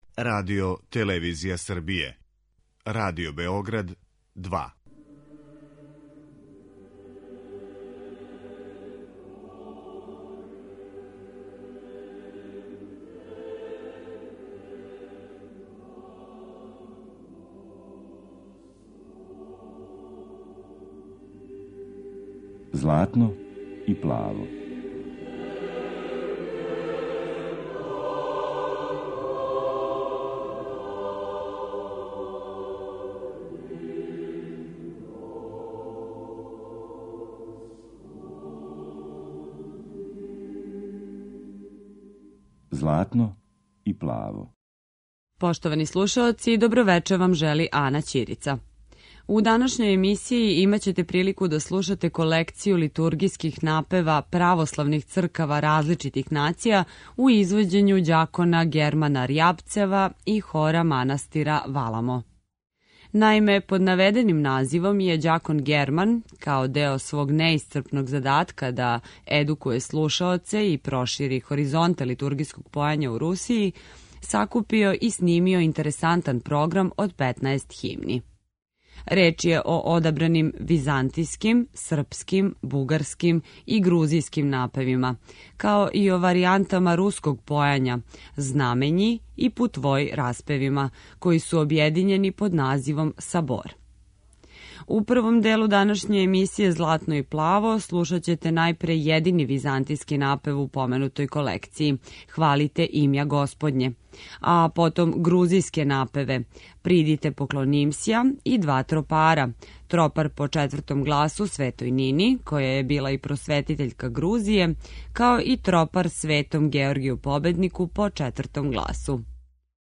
литургијских напева православних цркава различитих нација